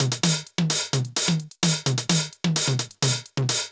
CLF Beat - Mix 15.wav